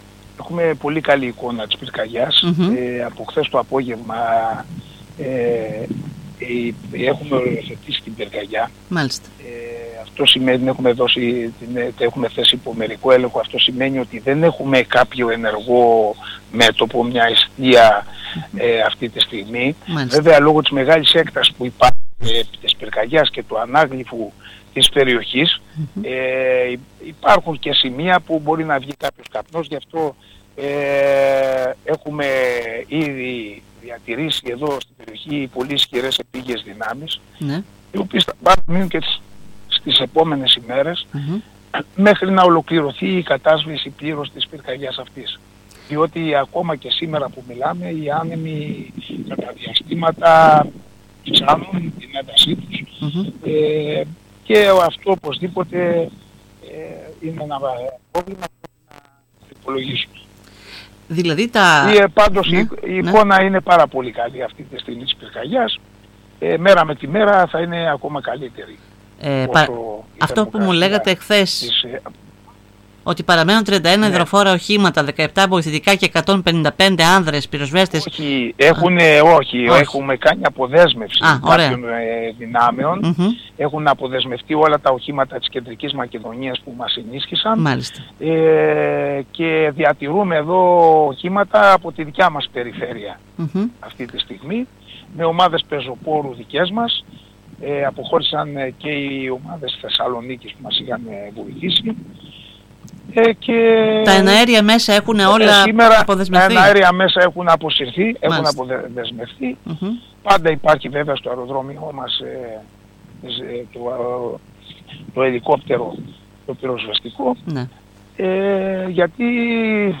Σύμφωνα με δηλώσεις στην ΕΡΤ Ορεστιάδας του Διοικητή της Περιφερειακής Πυροσβεστικής ΑΜ-Θ κ. Κώστα Κούκουρα «η εικόνα σήμερα είναι πολύ καλή» ,ενώ οι επίγειες δυνάμεις της Κεντρικής Μακεδονίας έχουν αποδεσμευθεί καθώς και τα εναέρια μέσα εκτός του πυροσβεστικού ελικοπτέρου του Αεροδρομίου Δημόκριτος